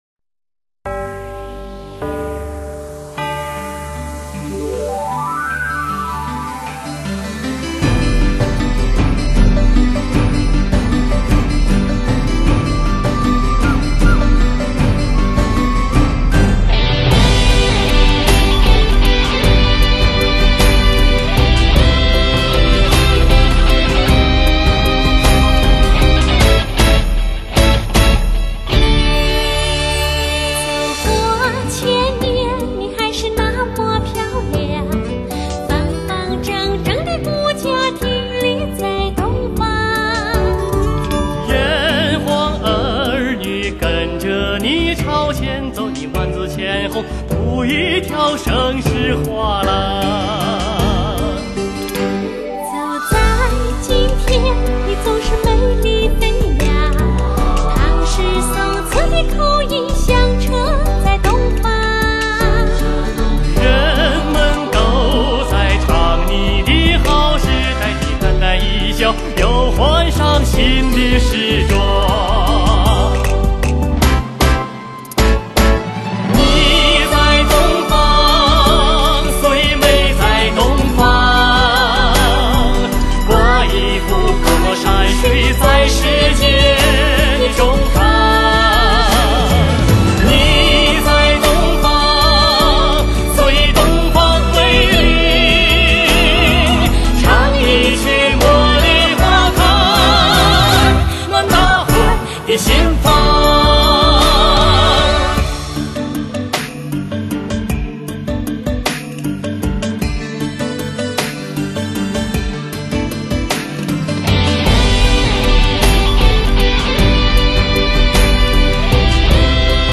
民歌新天后